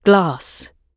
glass-gb.mp3